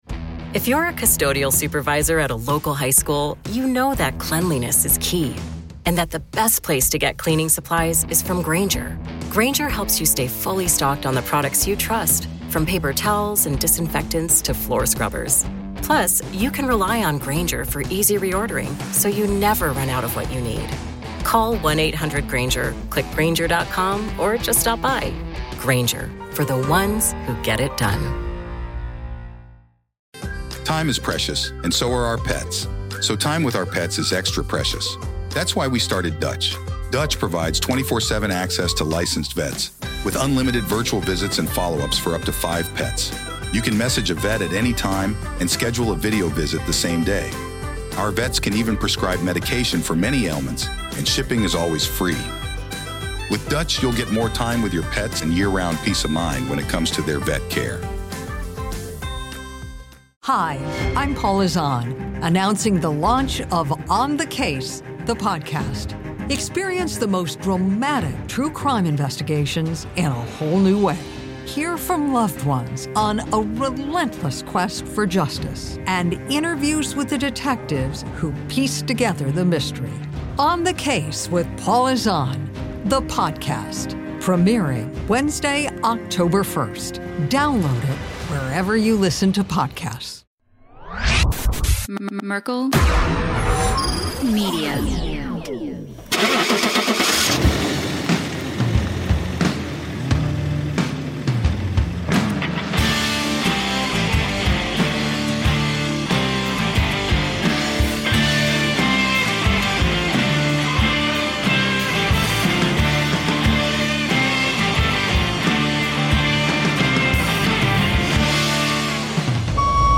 There was no shortage of laughter, candid reflections, and insightful discussions throughout this week's show!